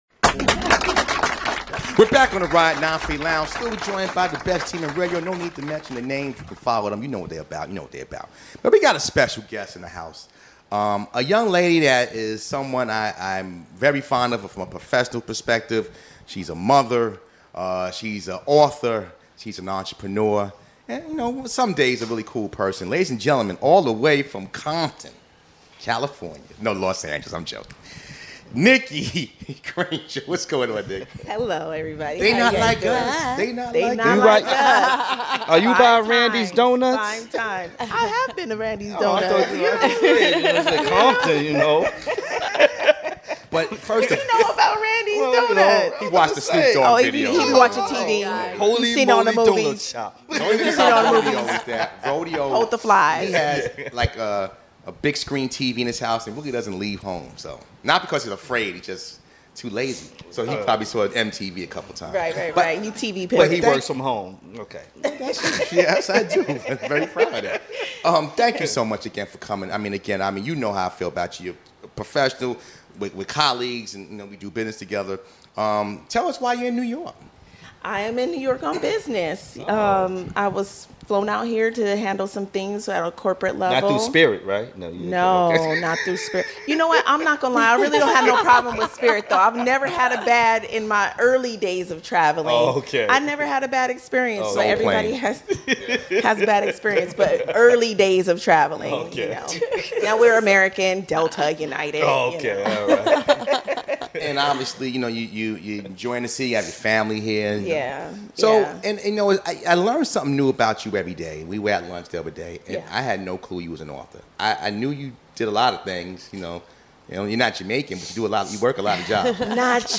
The 950 Lounge Show is a discussion on ALL THINGS relevant. Everything from Entertainment to Politics is discussed with NOTHING off base.